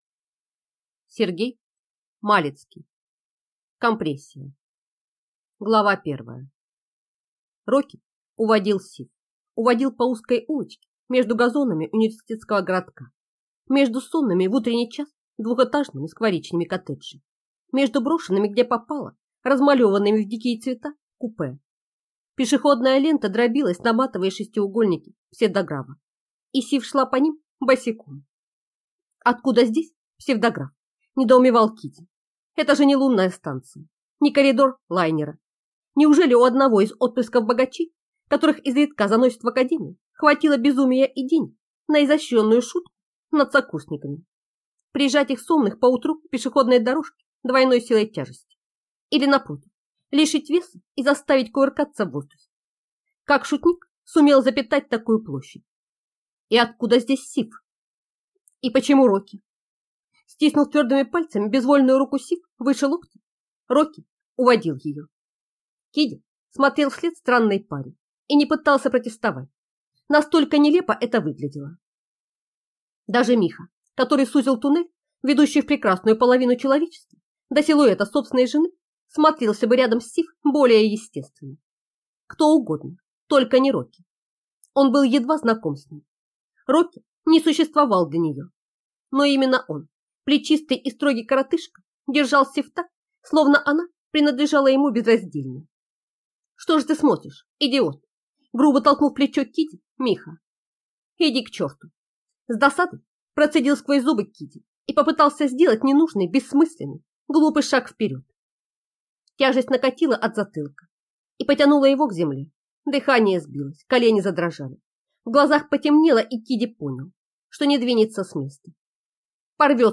Aудиокнига Компрессия